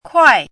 chinese-voice - 汉字语音库
kuai4.mp3